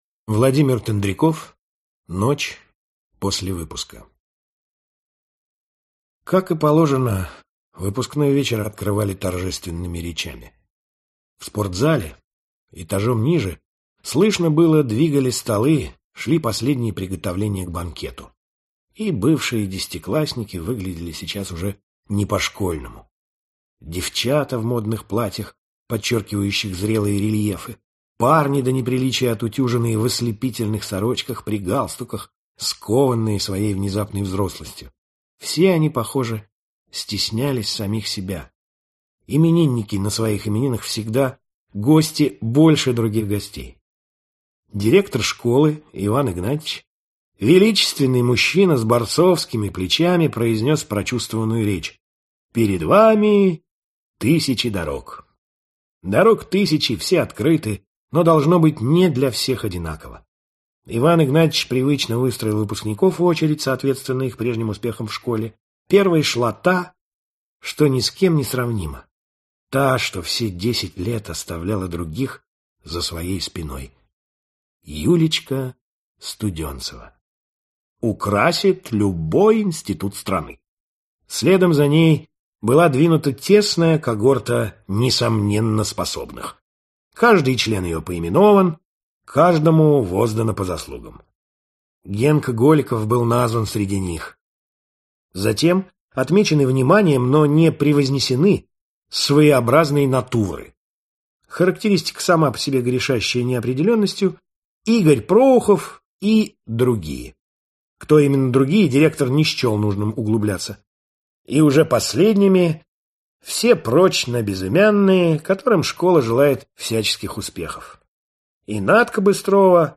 Аудиокнига Ночь после выпуска | Библиотека аудиокниг
Aудиокнига Ночь после выпуска Автор Владимир Тендряков Читает аудиокнигу Николай Фоменко.